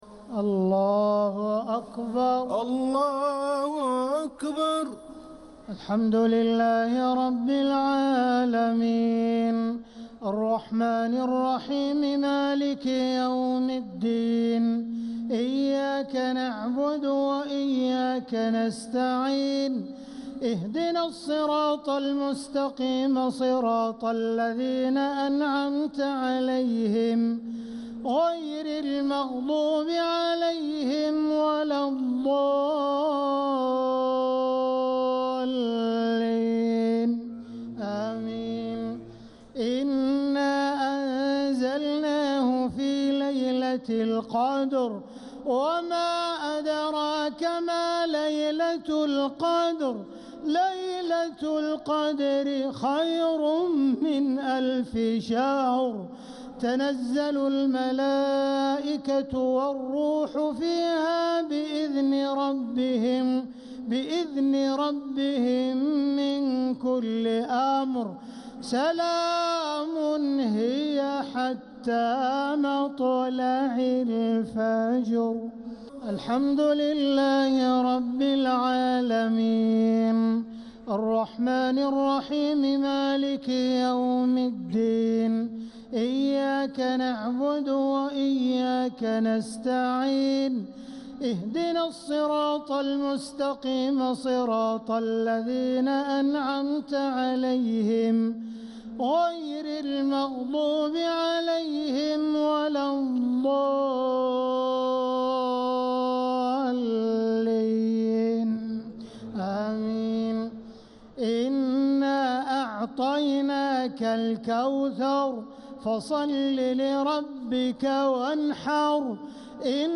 صلاة الشفع و الوتر ليلة 27 رمضان 1446هـ | Witr 27th night Ramadan 1446H > تراويح الحرم المكي عام 1446 🕋 > التراويح - تلاوات الحرمين